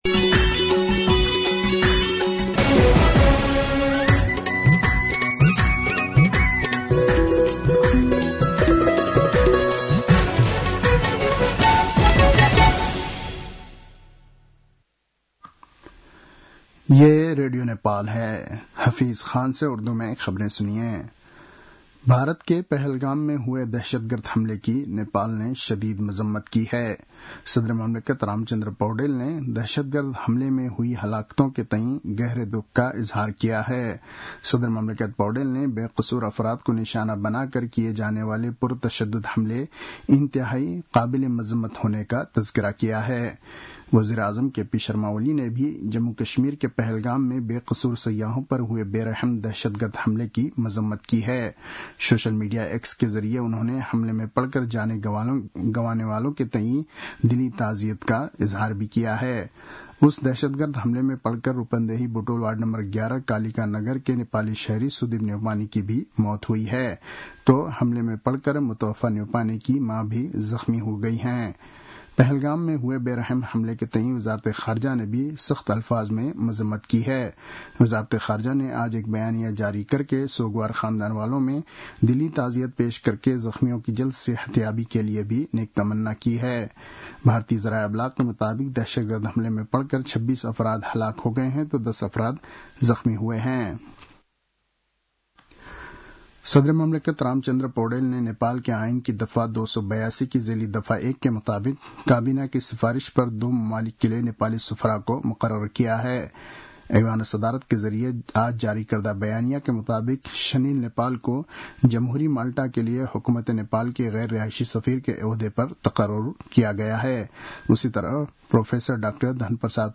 उर्दु भाषामा समाचार : १० वैशाख , २०८२
Urdu-news-1-10.mp3